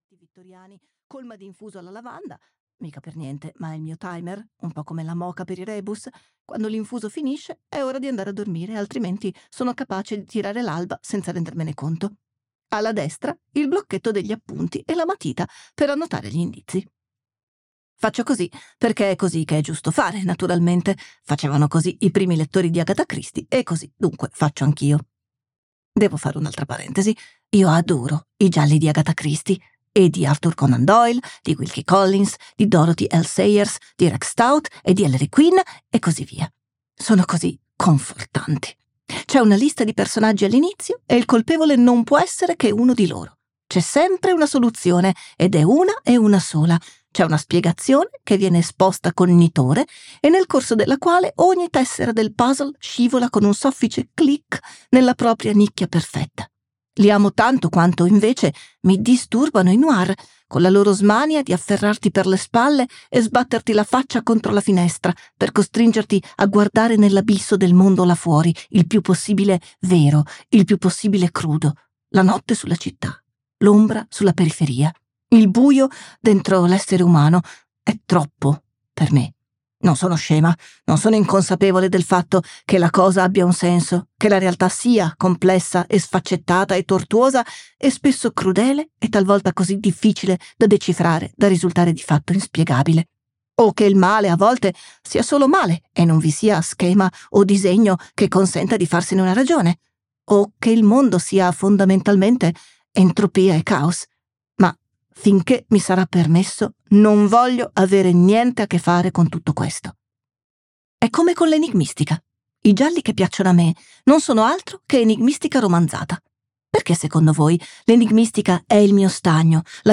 "Le ventisette sveglie di Atena Ferraris" di Alice Basso - Audiolibro digitale - AUDIOLIBRI LIQUIDI - Il Libraio
• Letto da: Alice Basso